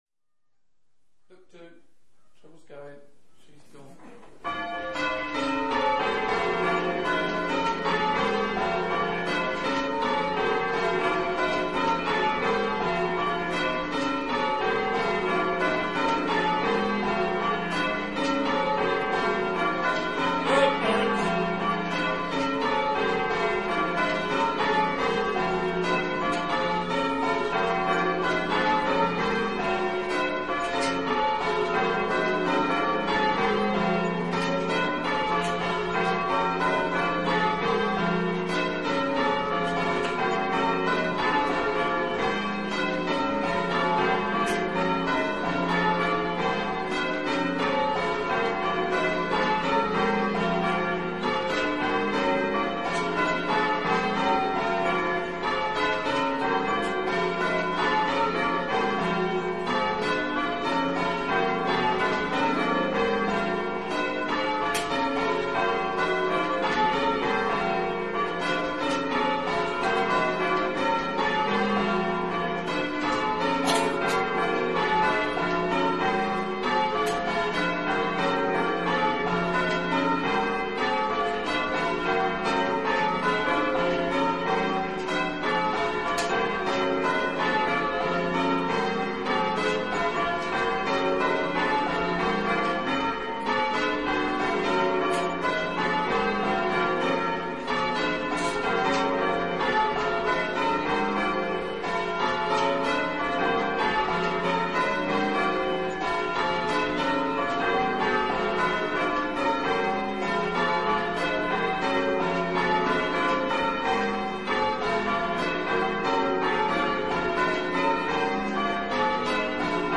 6 bells, 15-0-0 in E Access by stairs